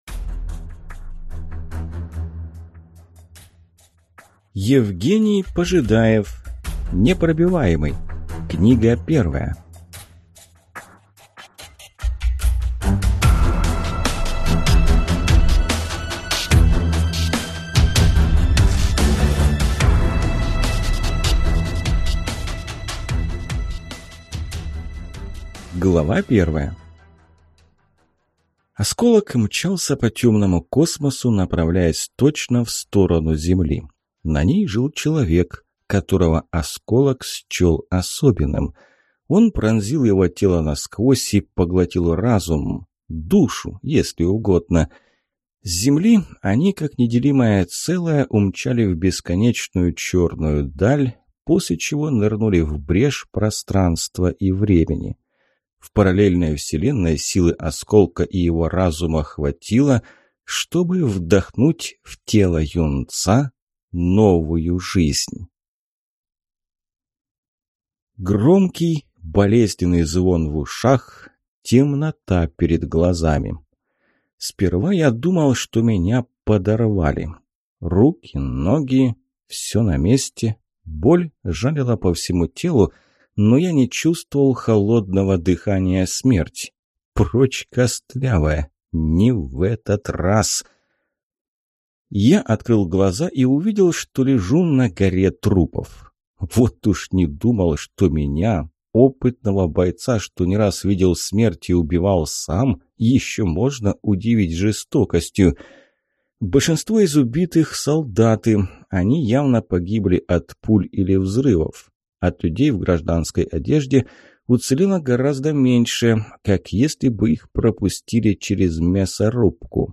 Аудиокнига Непробиваемый. Книга 1 | Библиотека аудиокниг